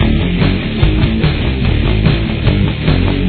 Main Riff
This song is in drop-D tuning.